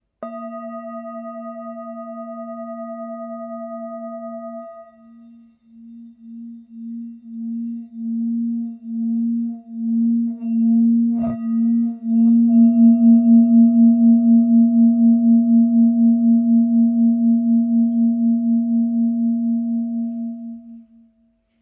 Tepaná tibetská mísa Chuto o hmotnosti 1094 g. Mísa je včetně paličky s kůží!
Lahodné dlouhotrvající tóny tibetské mísy nám umožňují koncentrovat naši mysl, relaxovat a uvolnit naše tělo.
tibetska_misa_s26.mp3